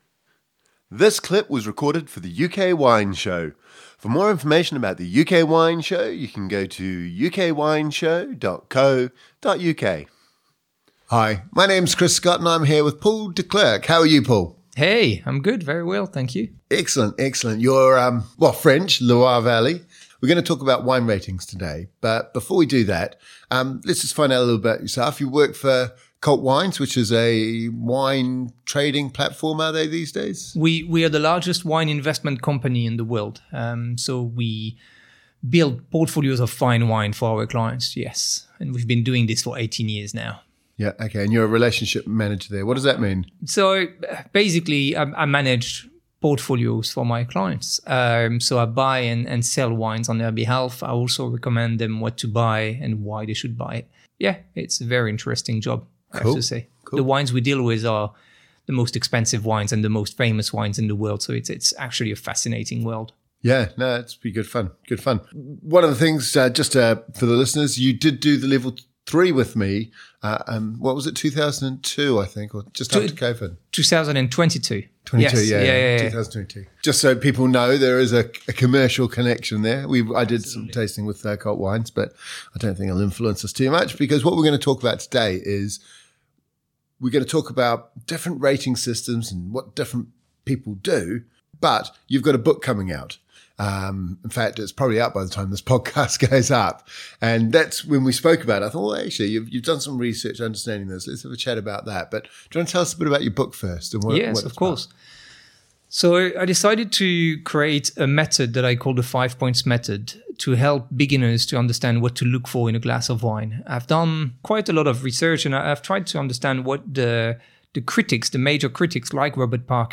In this interview we discuss different rating systems for wines created and used by wine critics to evaluate wines and score them for consumers. Robert Parker scores out of 100, and Jancis Robinson scores out of 20.